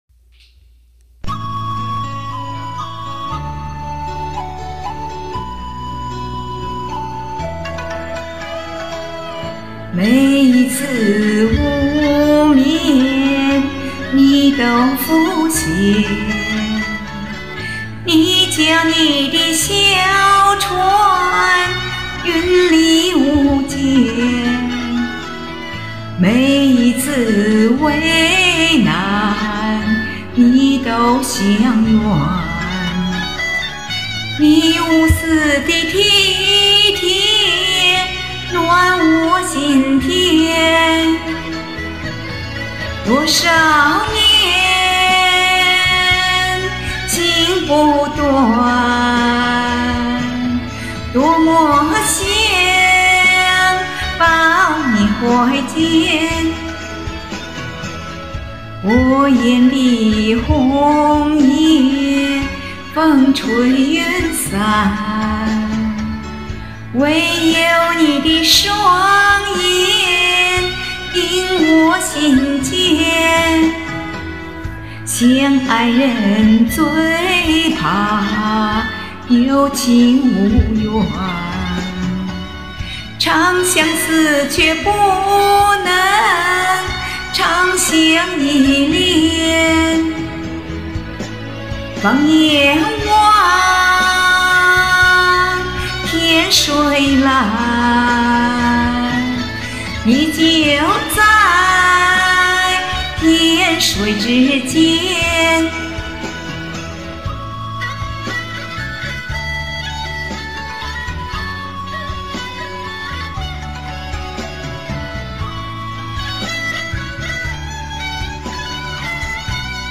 京腔京韵，情深意绵，舒展婉转
甜美温柔的嗓音，韵味十足的演唱！